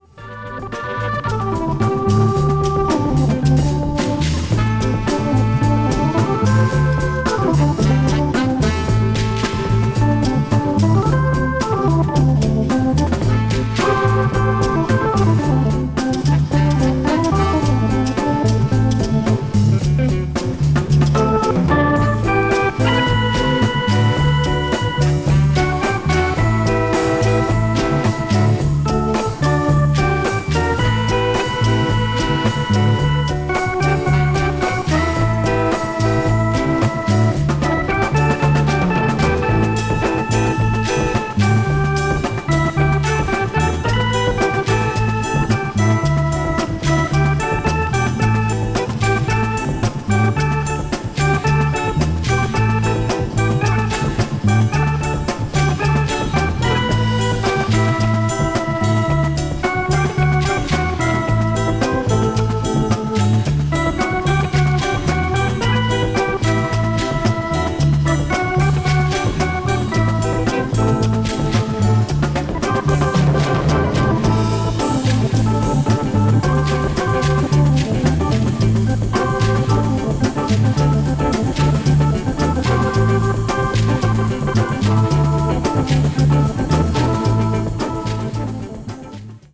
media : EX-/EX-(薄いスリキズによるわずかなチリノイズが入る箇所あり)
blues jazz   jazz funk   soul jazz